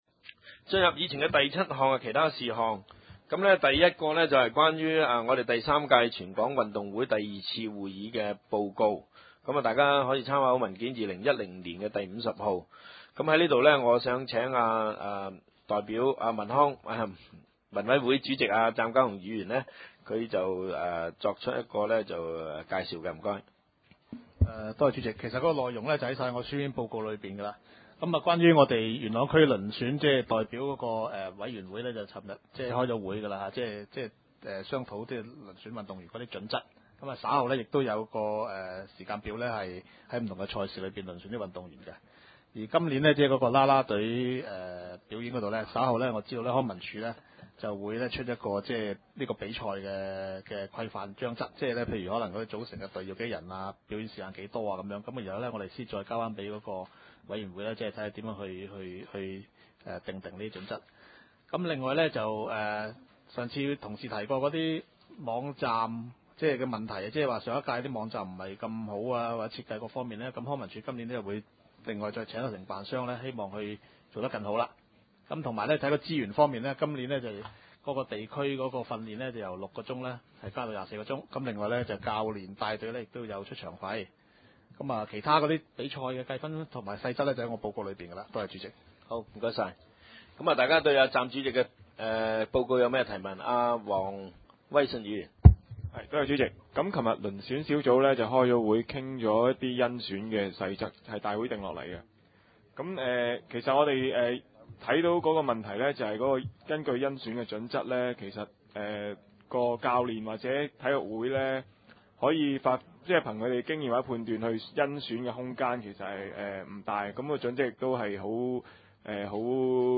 點：元朗橋樂坊二號元朗政府合署十三樓元朗區議會會議廳